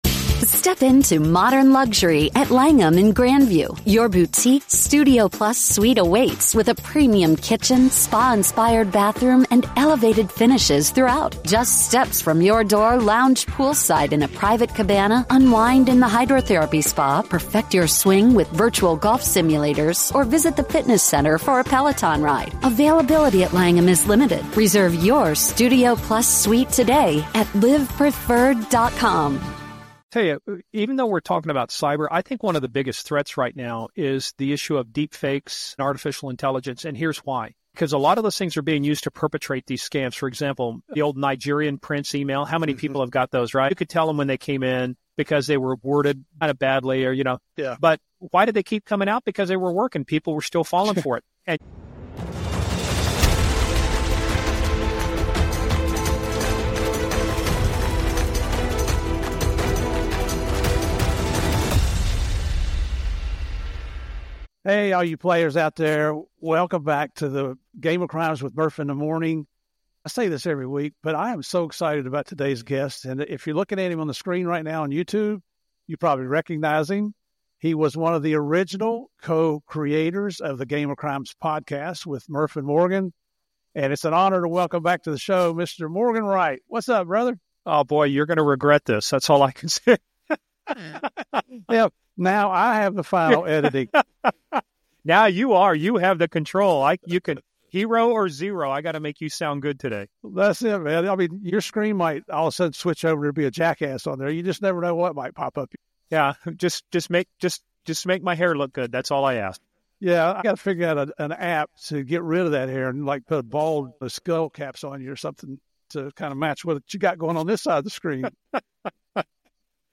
The conversation is filled with humor, anecdotes, and a deep understanding of crime and law enforcement.